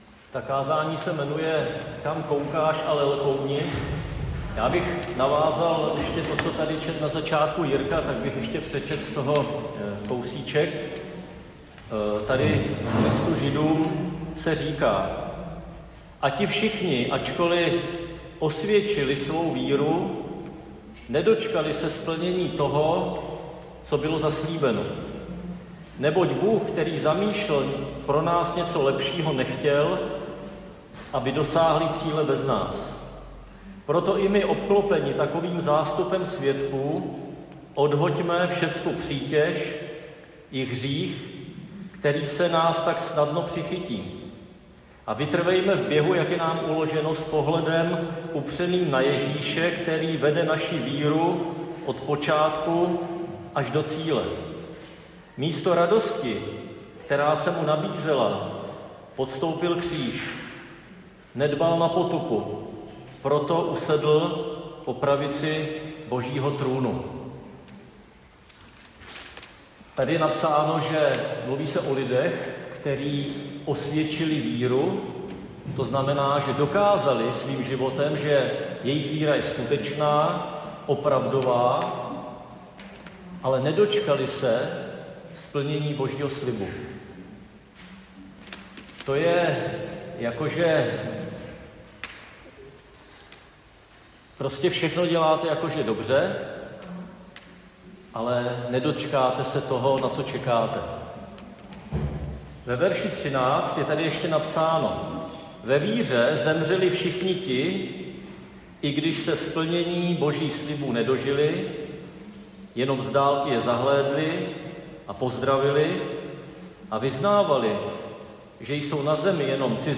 Křesťanské společenství Jičín - Kázání 22.6.2025